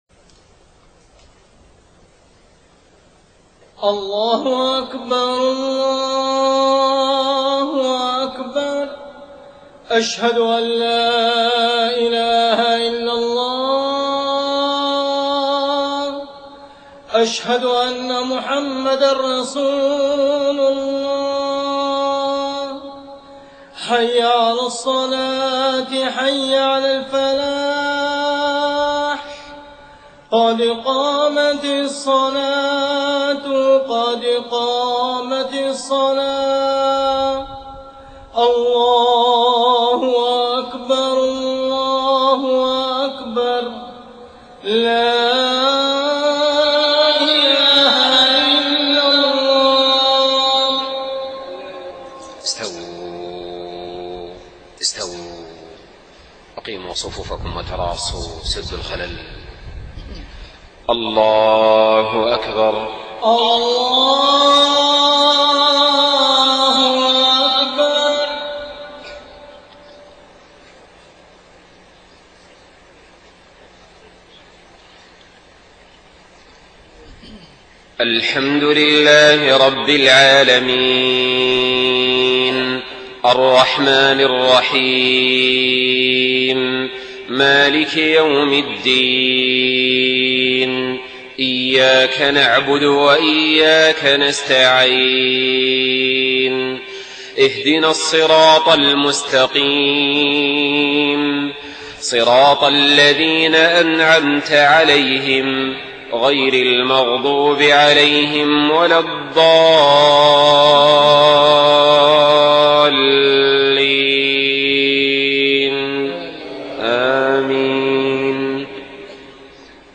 صلاة العشاء 3 محرم 1430هـ من سورة الأحزاب 41-48 و 56-58 > 1430 🕋 > الفروض - تلاوات الحرمين